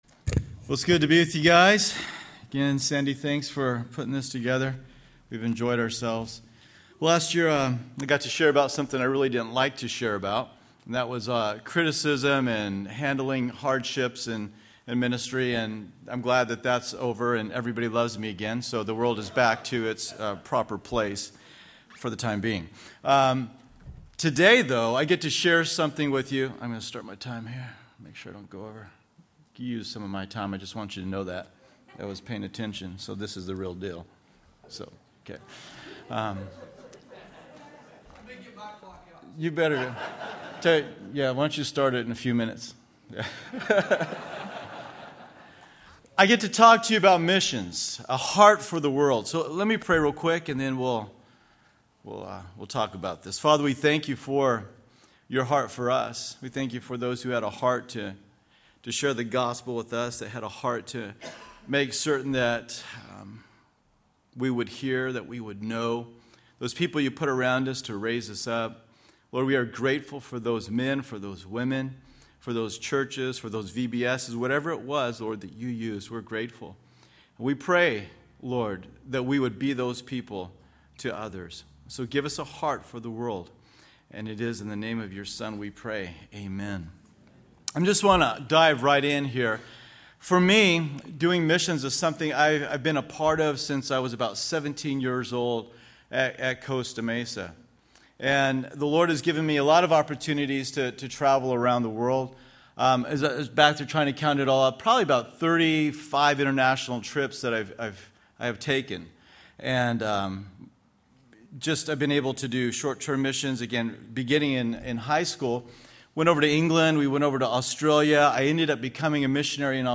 2011 DSPC Conference: Pastors & Leaders Date